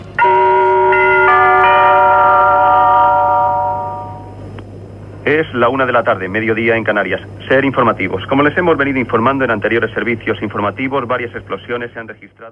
Sintonia del programa i entrada del locutor.
Informatiu